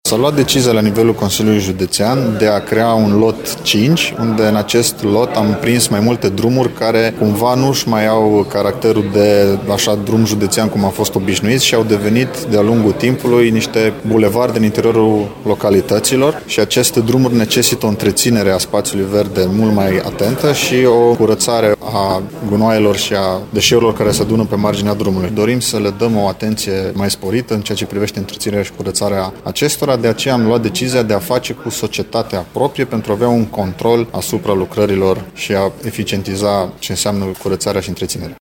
Cele cinci artere intens circulate necesită o atenție mai ridicată în ceea ce privește igienizarea sau cosirea ierbii de pe marginea acestora, spune vicepreședintele Consiliului Județean Timiș, Alexandru Proteasa.